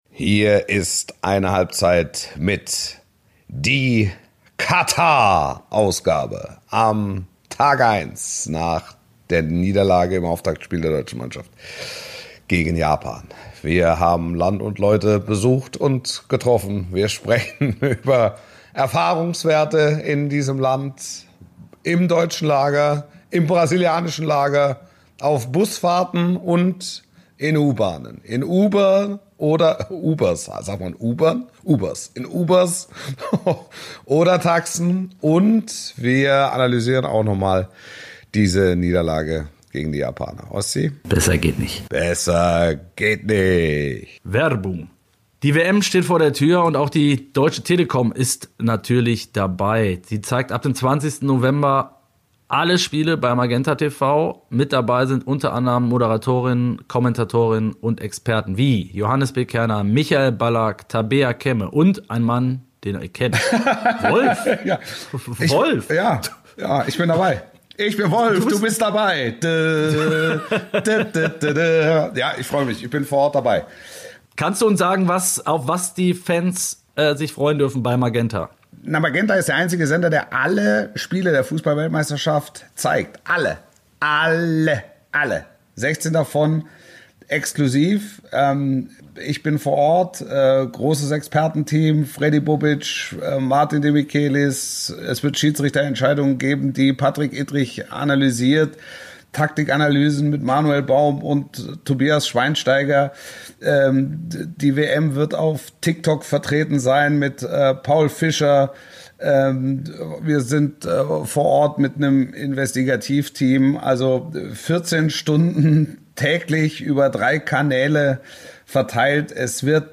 Die Welttorhüterin von 2014 und Olympiasiegerin 2016 mit Deutschlands Fußballerinnen Almuth Schult stellt hier ihr Thema oder ihre These der Woche vor.
Und dann wird diskutiert.